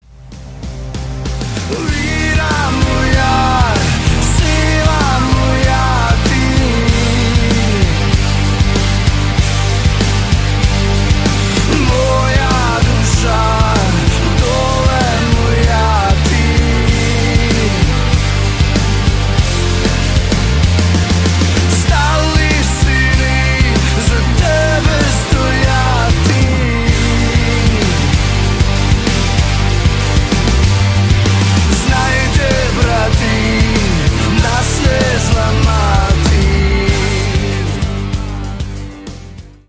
мужской вокал
Alternative Rock
сильные
патриотические